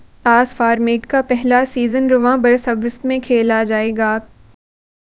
deepfake_detection_dataset_urdu / Spoofed_TTS /Speaker_05 /13.wav